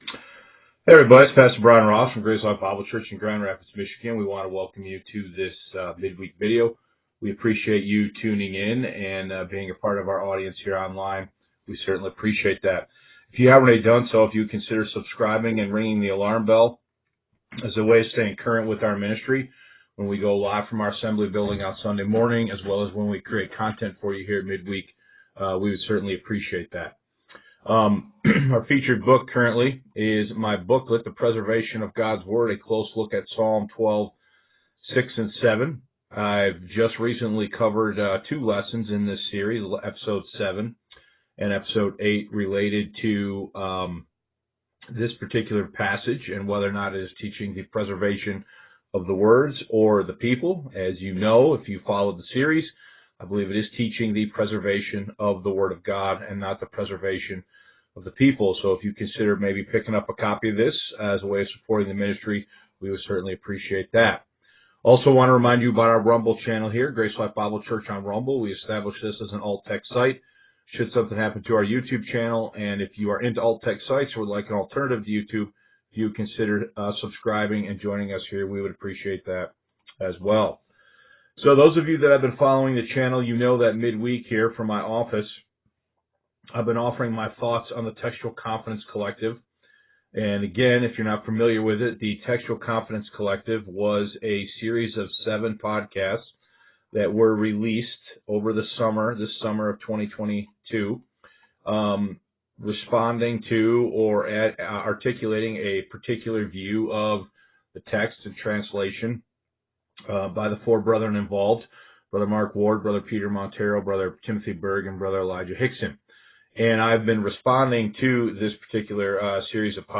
Mid-Week Messages